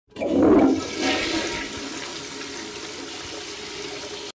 Skibidi Toilet Flush Sound Effect Free Download
Skibidi Toilet Flush